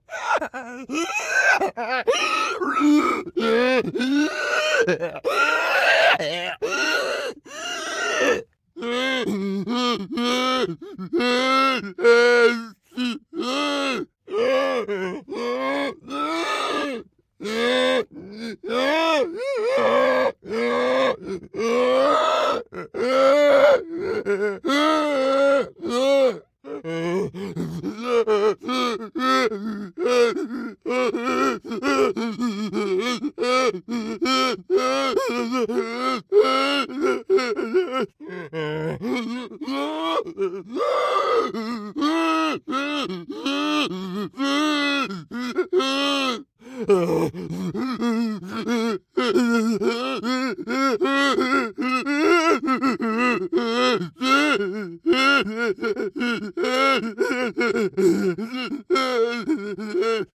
Cannibal_Breathing.ogg